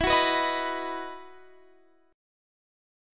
EM7sus2 Chord (page 2)
Listen to EM7sus2 strummed